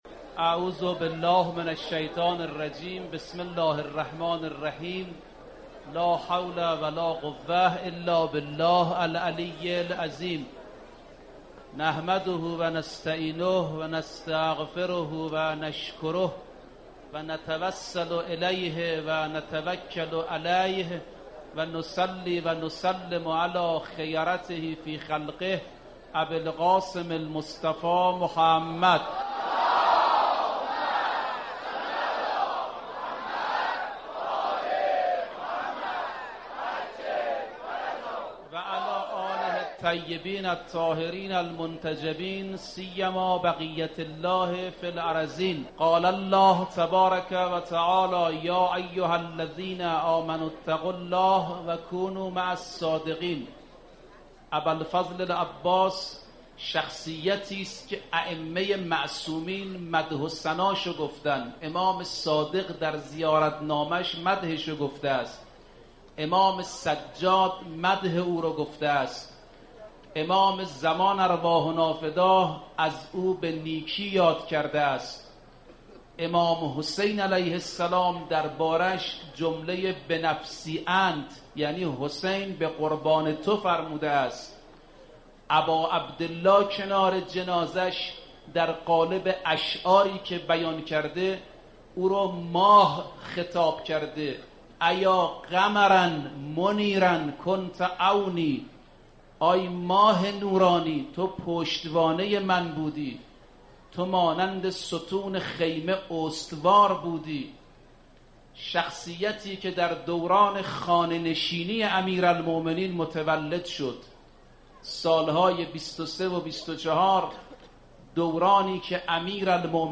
خلاصه متن سخنرانی